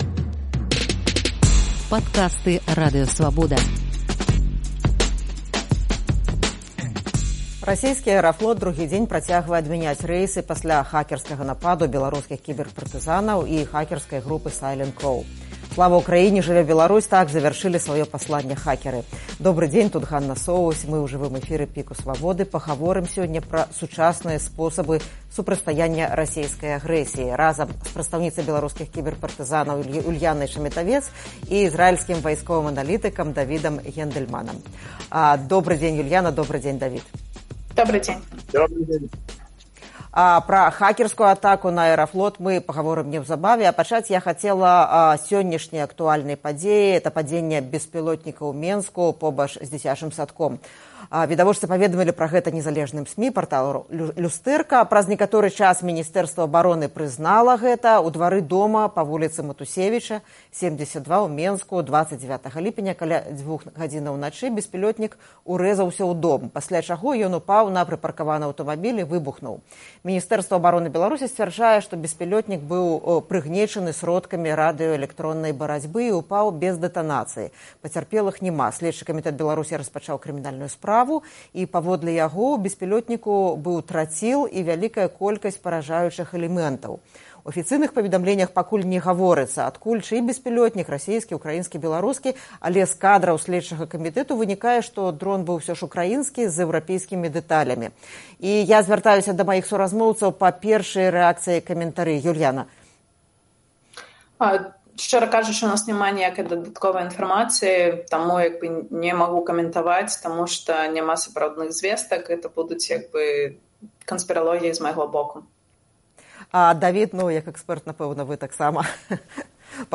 У жывым эфіры «ПіКа» Свабоды